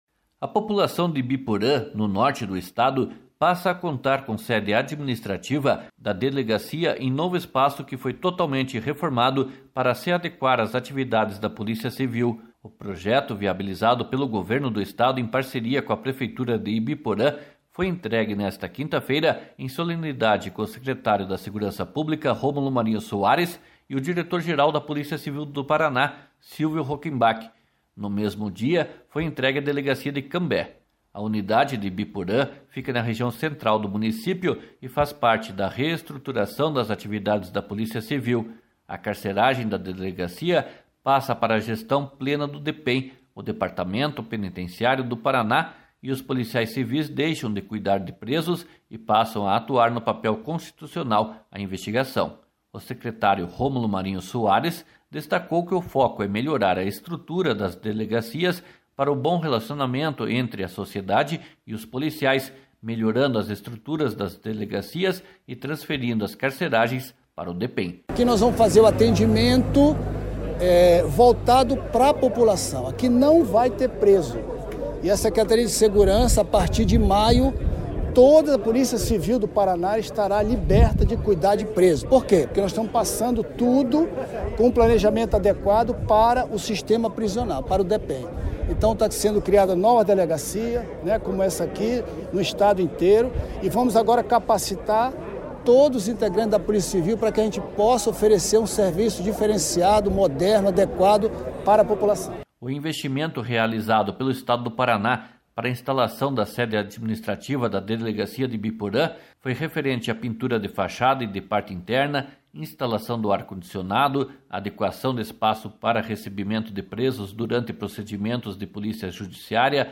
O secretário Romulo Marinho Soares, destacou que o foco é melhorar a estrutura das delegacias para o bom relacionamento entre a sociedade e os policiais, melhorando as estruturas das delegacias e transferindo as carceragens para o Depen. //SONORA ROMULO MARINHO SOARES//